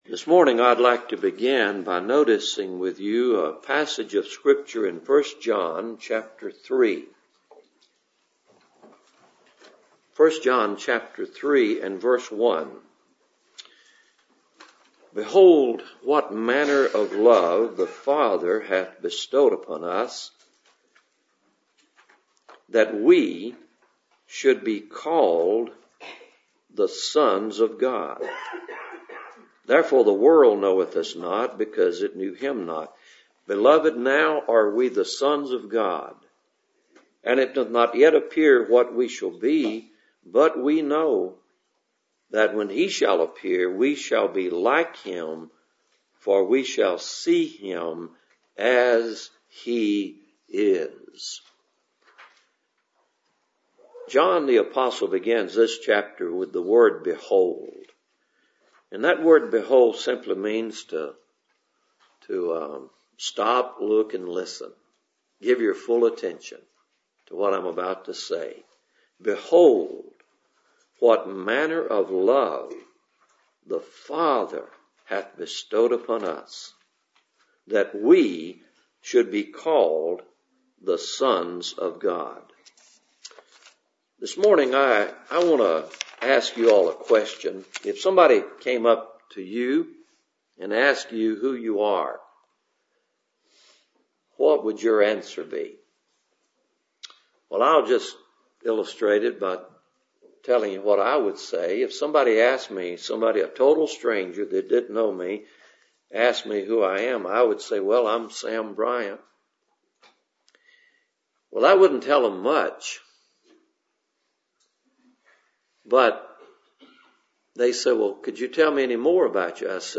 Passage: 1 John 3:1-2 Service Type: Cool Springs PBC Sunday Morning